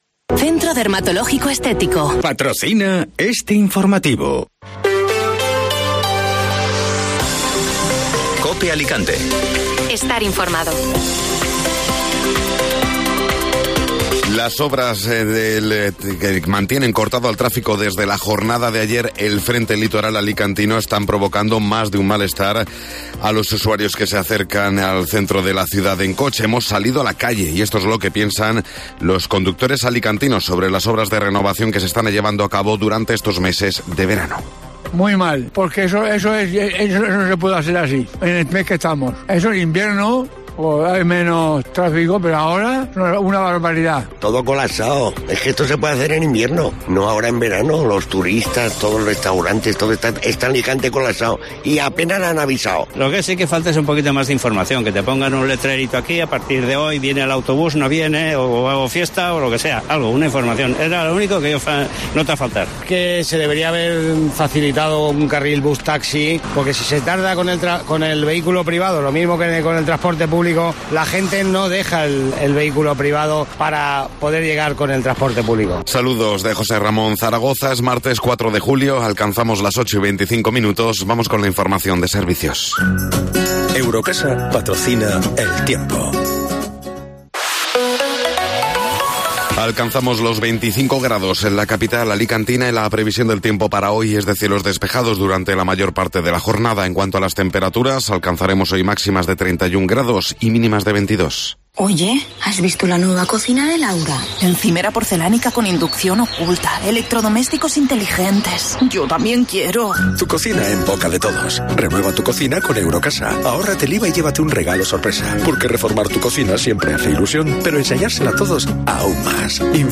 Hemos salido a la calle y esto es lo que piensan los alicantinos sobre las obras de renovación que se están llevando a cabo durante estos meses de verano